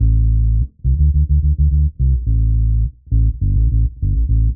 Index of /musicradar/dub-designer-samples/105bpm/Bass
DD_PBass_105_A.wav